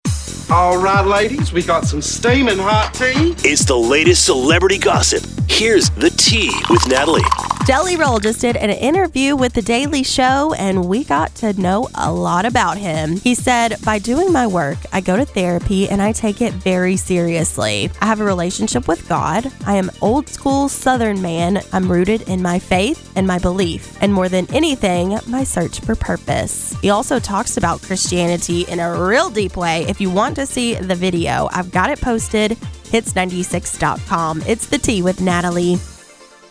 (The interview was with “Daily Show” guest host Charlamagne Tha God.  We cued it up to the therapy part of the video.)